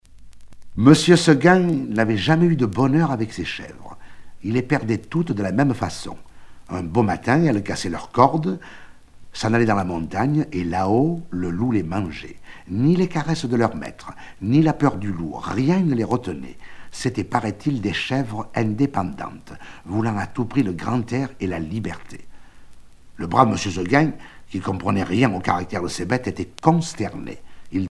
Genre récit